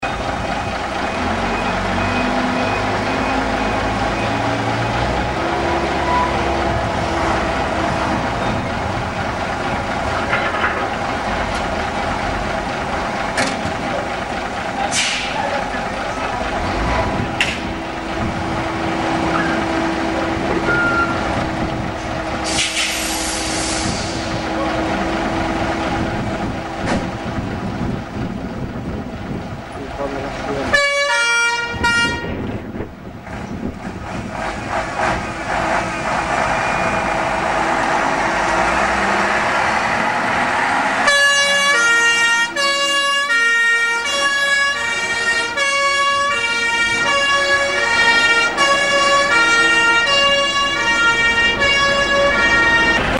�arranque, acelerando, en marcha y sirena.
SONIDO PEGASO 3046 EN MARCHA Y SIRENA.mp3